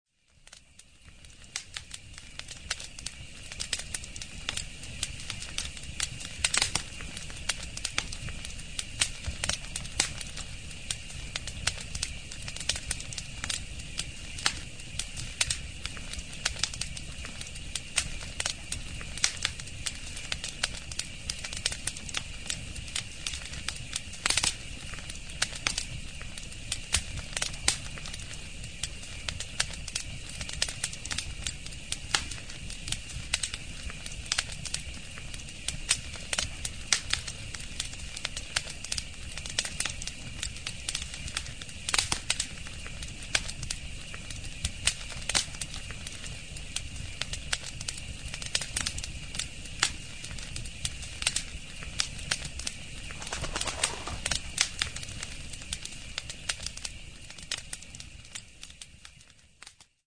Campfire